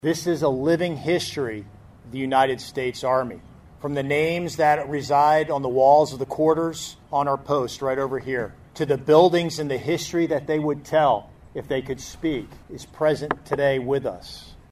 1st Infantry Division Commanding General John Meyer III also spoke Tuesday, saying “museums honor where the army has been.”